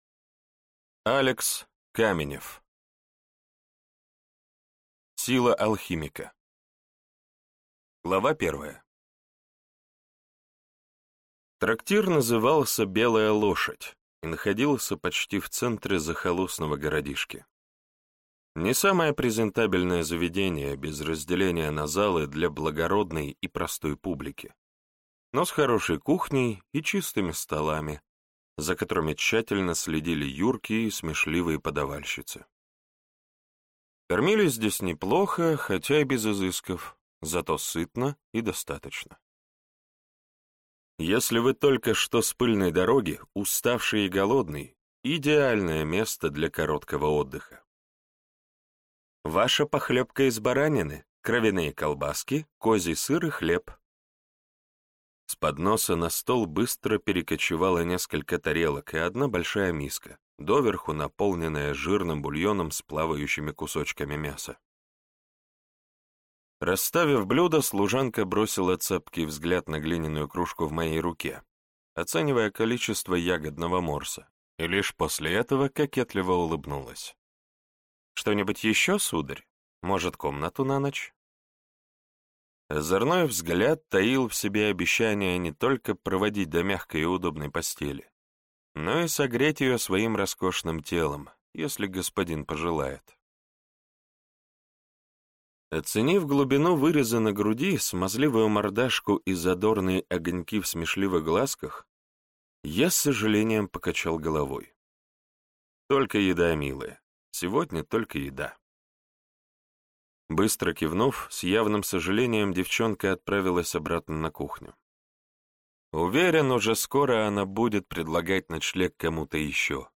Аудиокнига Сила алхимика | Библиотека аудиокниг